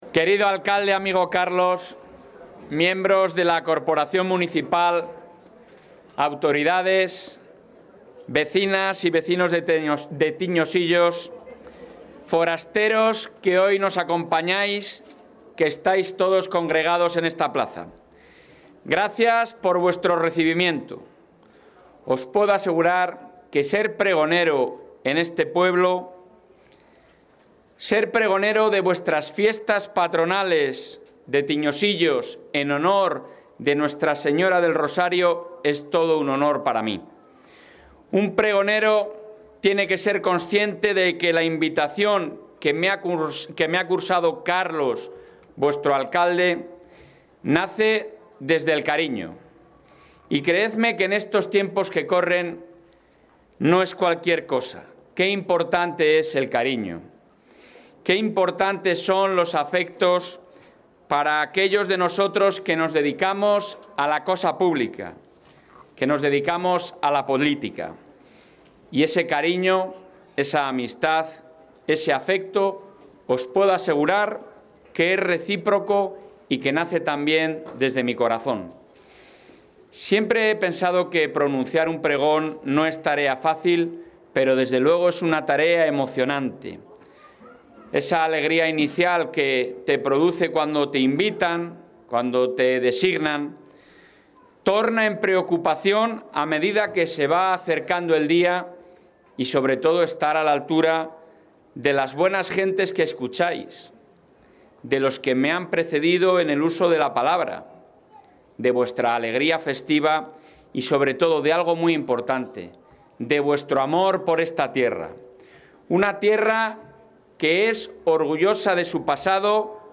Intervención del presidente.
El presidente de la Junta de Castilla y León ha pronunciado esta tarde el pregón de las fiestas patronales de la localidad abulense de Tiñosillos, donde ha reiterado su compromiso de trabajar para mejorar las condiciones de vida de toda la población de la Comunidad, especialmente en el medio rural.